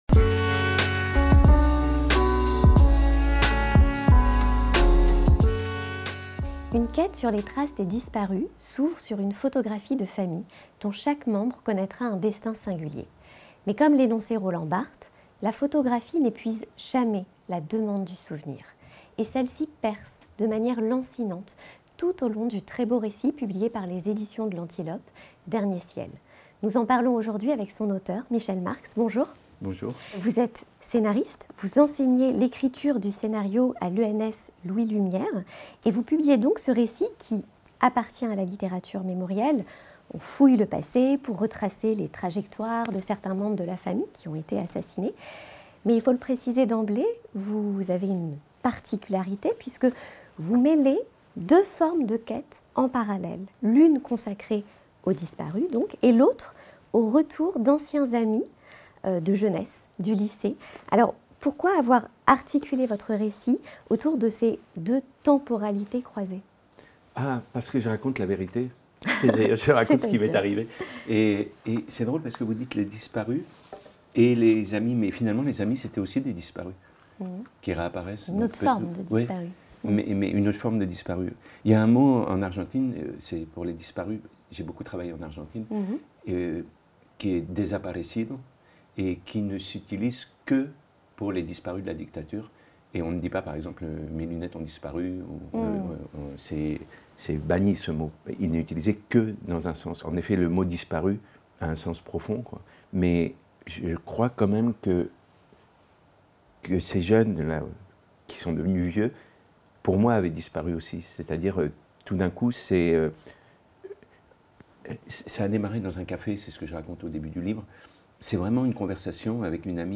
Entretien Peut-on aimer ceux que l'on n'a pas connus ?